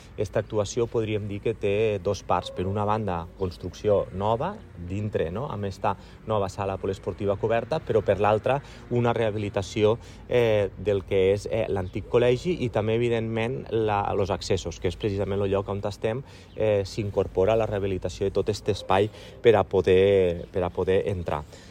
Jordan ens explica en què consistiran les dos fases.